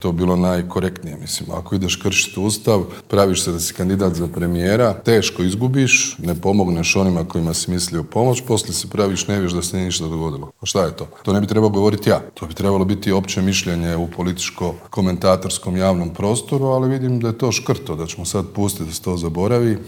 ZAGREB - Premijer, ministri, šefovi oporbenih stranaka, gradonačelnici - svi oni bili su gosti Intervjua tjedna Media servisa.
Kao što znamo, pobjedu je odnio HDZ i njegovi partneri, a novi-stari premijer Andrej Plenković Media servisu dao je prvi radijski intervju otkako je treći put uzastopno sjeo na tu funkciju. Odmah nakon izbora poručio je da je vrijeme da predsjednik Zoran Milanović podnese ostavku: